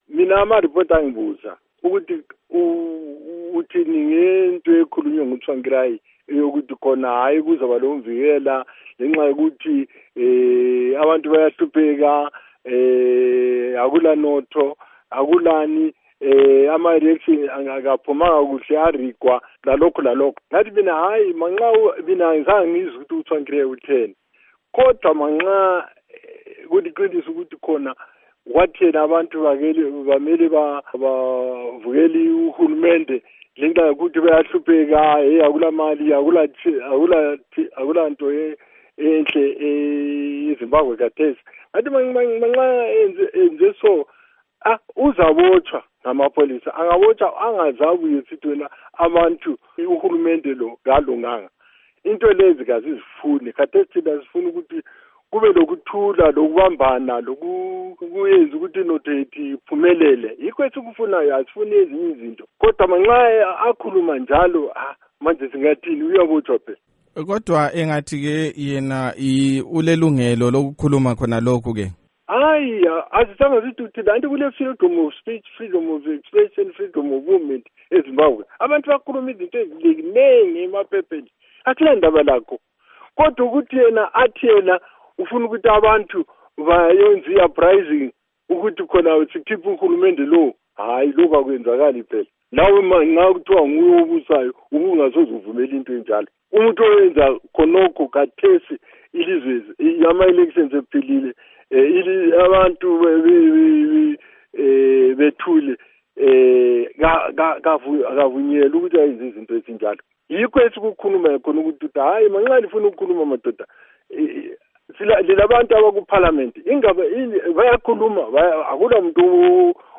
Ingxoxo loMnu.Rugare Gumbo